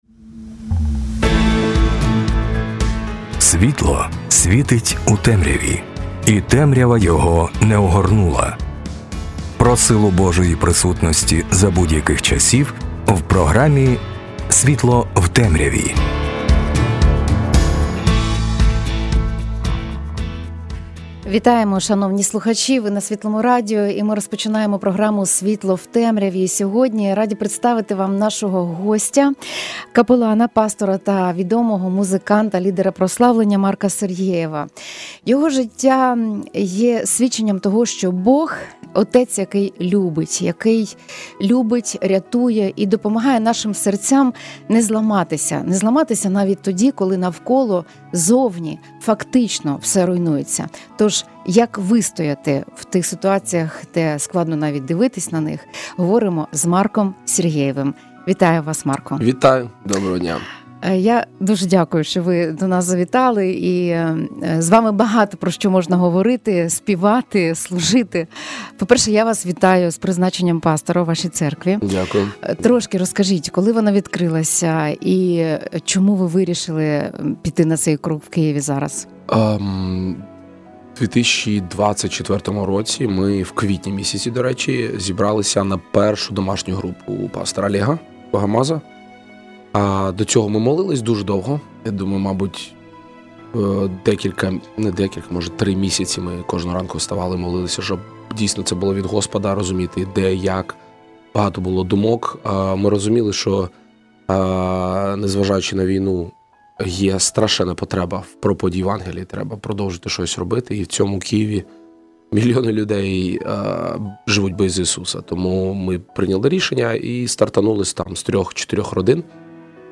Це глибока розмова про подолання страху, виховання «дітей війни» та здатність співати Богу хвалу навіть на руїнах власного будинку.